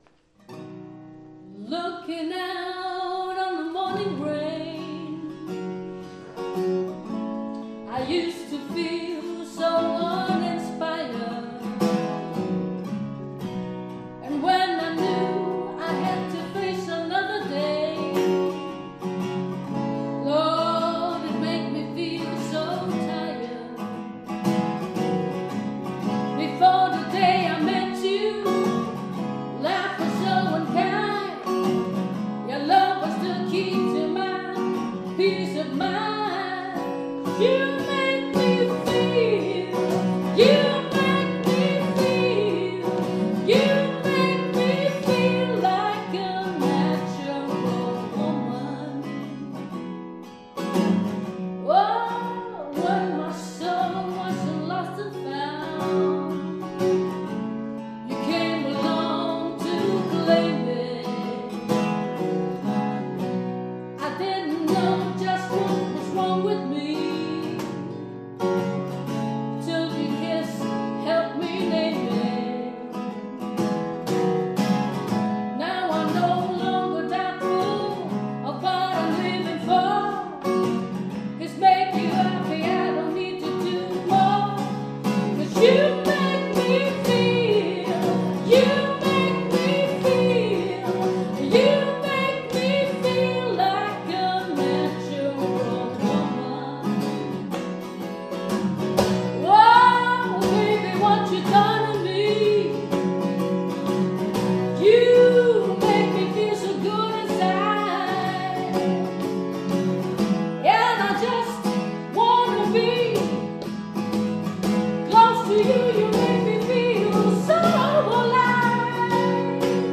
aus der Pfarrkirche Karnburg
Rockmesse im Mai 2 MB Psalm (Carol King) Emmausweg mit Firmkandidaten 3 MB Schlussandacht Ostermontag in Lind 2 MB Karnburger Kirchenchor: Danklied Ostermontag in Lind 2 MB Karnburger Kirchenchor: Sanktus Ostermontag in Lind 3 MB Karnburger Kirchenchor: Christ ist erstanden